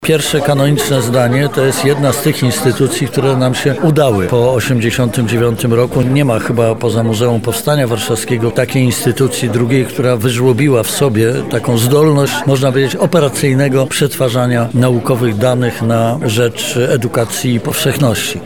Jan Żaryn– mówi prof. dr hab. Jan Żaryn